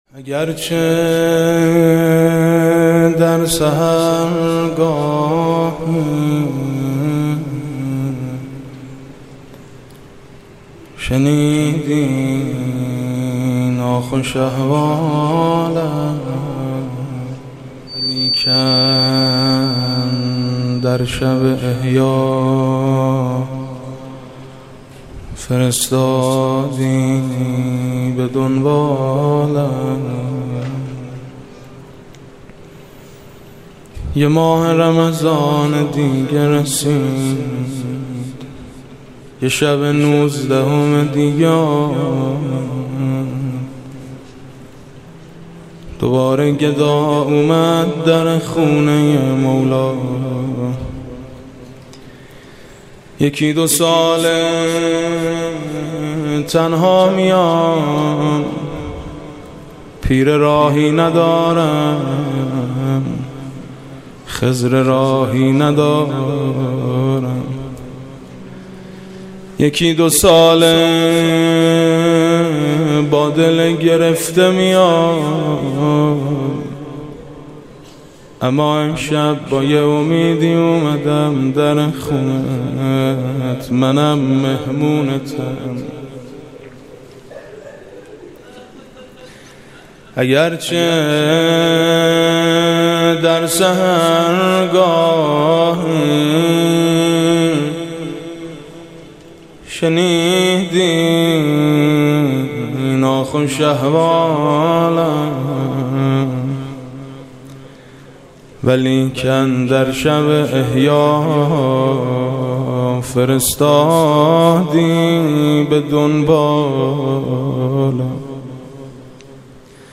اگر برآید چو مرغی ز پیکر خسته ام پر روضه محمود کریمی